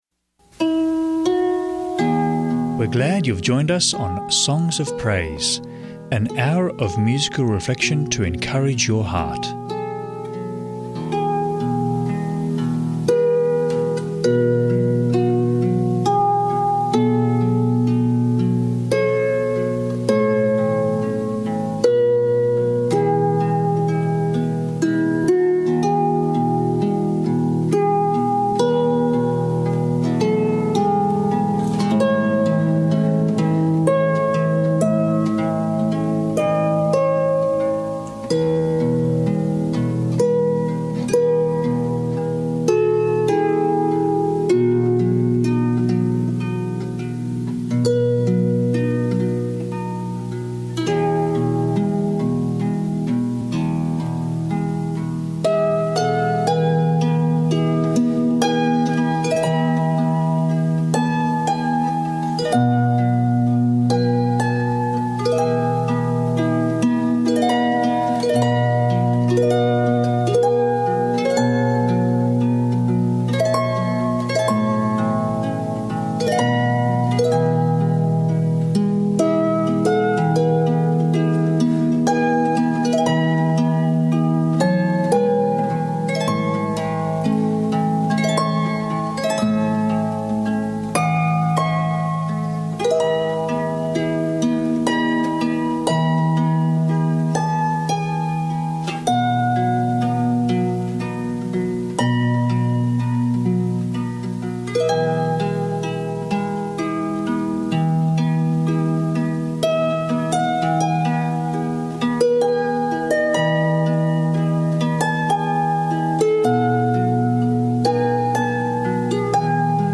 Enjoy 1 hour episodes of musical reflection to encourage, uplift and draw you into a closer relationship with our loving Saviour, Jesus Christ. Also featuring a short 3ABN Australia Radio Book Reading.